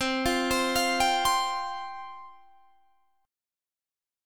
Listen to Csus4 strummed